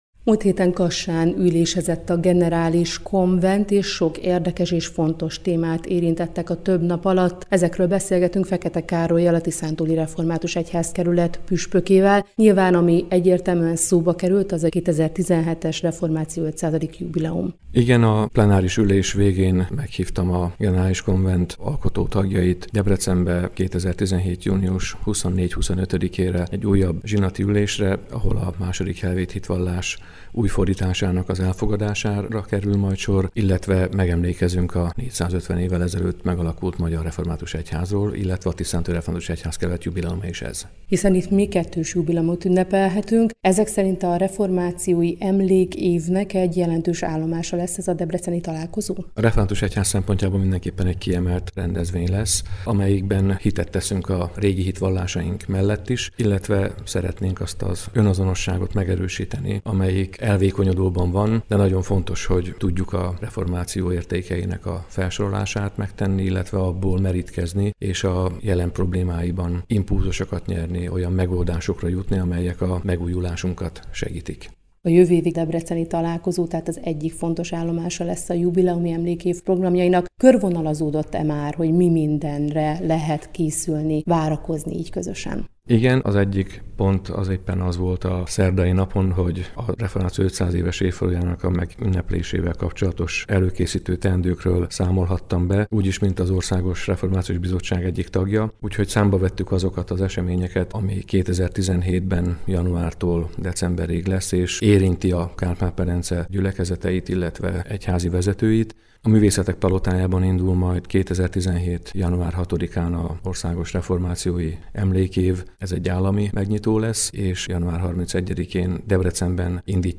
A Generális Konvent összegezte a jövőre tervezett emlékév kiemelt programjait, ezekben kiemelt szerep jut Debrecennek. Fekete Károly, a Tiszántúli Református Egyházkerület püspöke